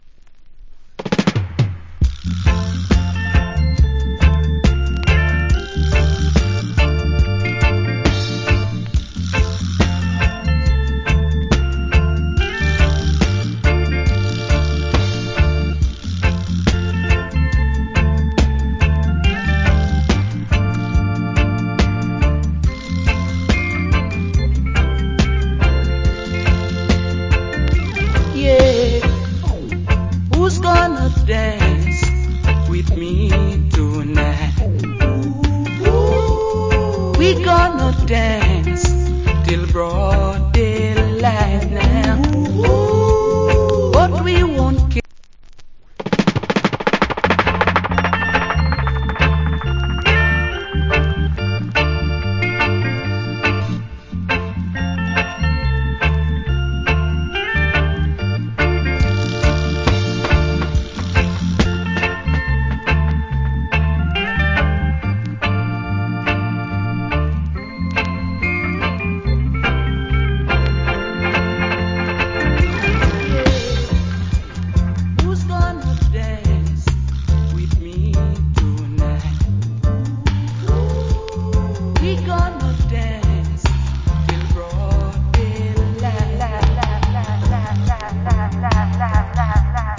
Good Reggae Vocal Plus DJ.